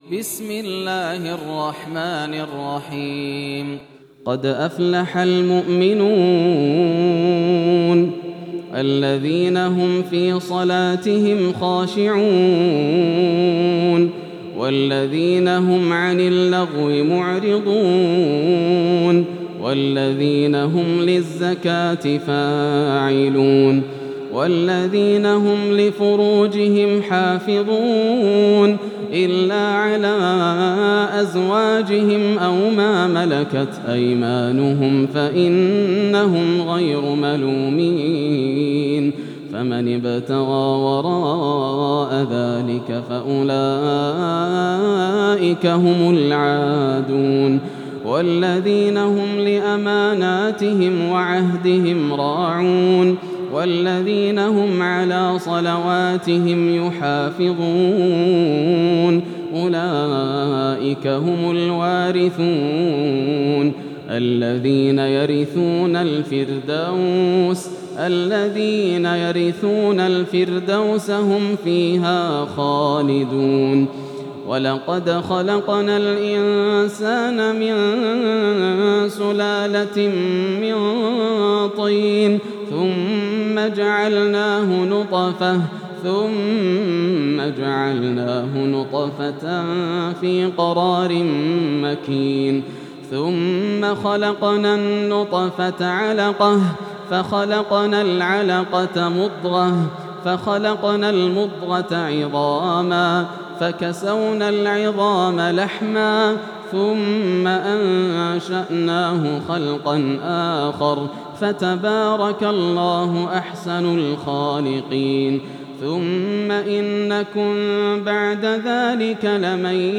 سورة المؤمنون > السور المكتملة > رمضان 1433 هـ > التراويح - تلاوات ياسر الدوسري